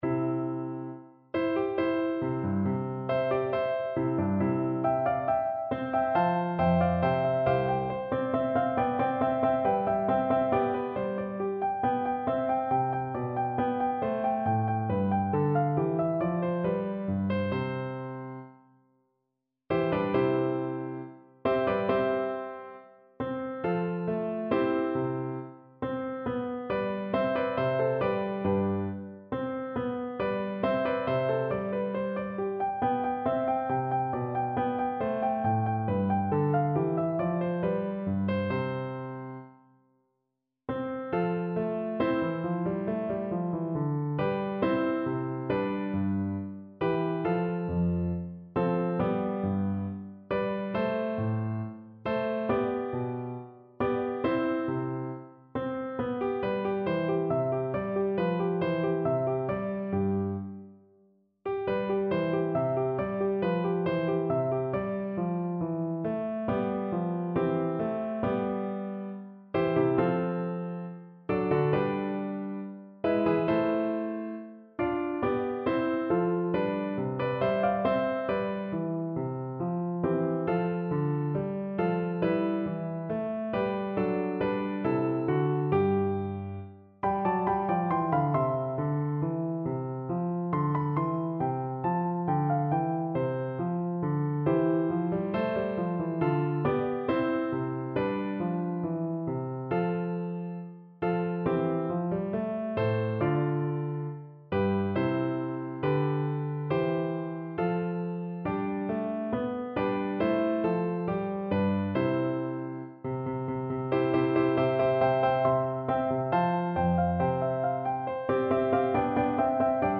4/4 (View more 4/4 Music)
[Allegro] =94 (View more music marked Allegro)
Classical (View more Classical Soprano Voice Music)